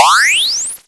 upgrade3.wav